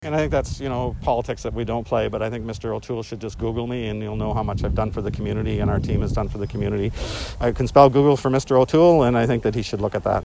Afterward Ellis told Quinte News he’s brought close to one billion dollars in infrastructure and other funding to the Bay of Quinte riding and has an important role as Parliamentary Secretary to the Minister of Agriculture.